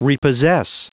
Prononciation du mot repossess en anglais (fichier audio)
Prononciation du mot : repossess